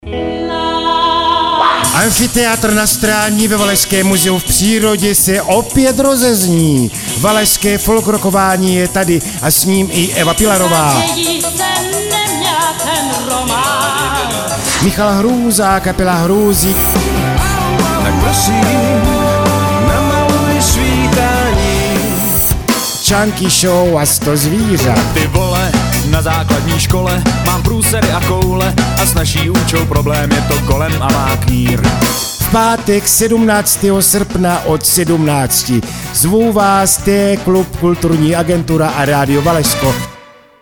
Spot Radio Valašsko |